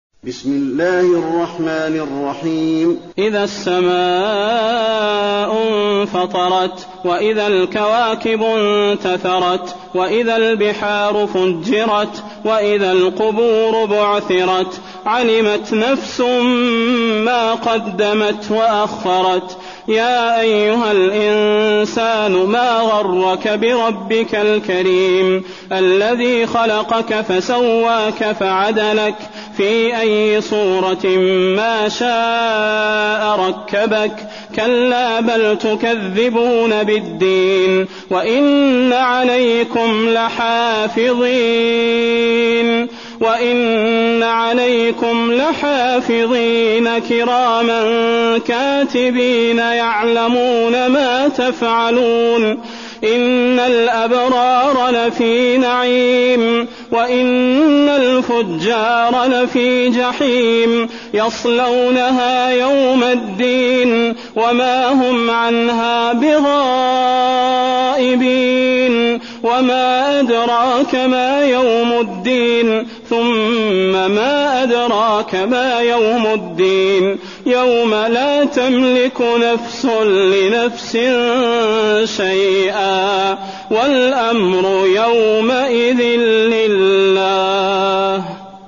المكان: المسجد النبوي الانفطار